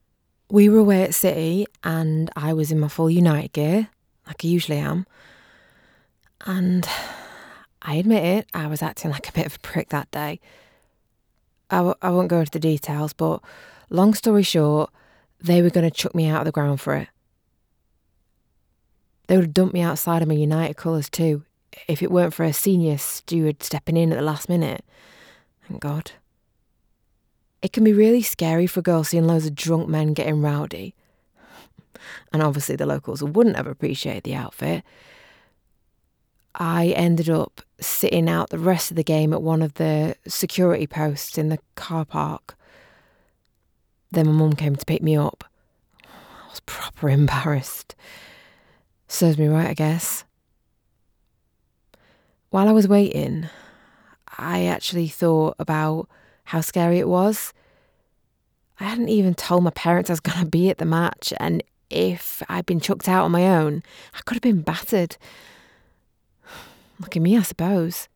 Natural, Versátil, Amable
E-learning
She has a broadcast quality home studio and is a popular choice amongst clients.